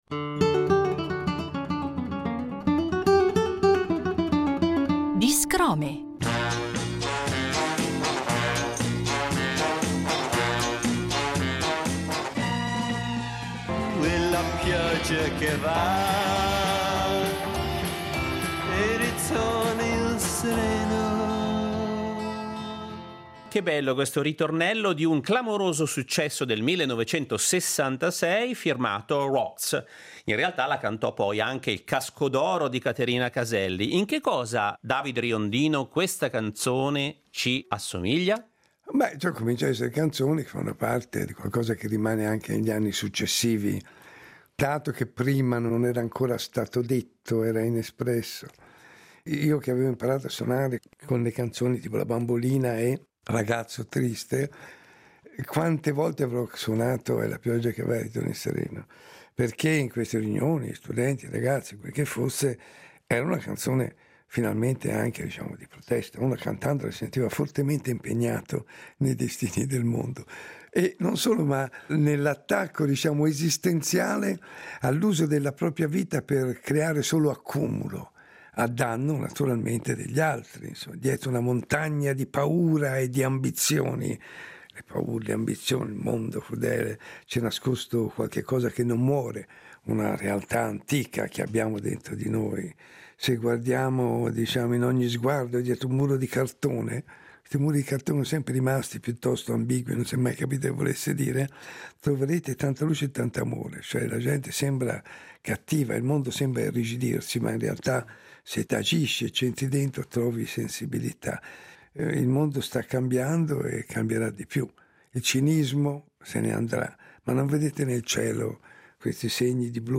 Percorriamo questi anni a suon di giradischi con i mirabolanti racconti di David Riondino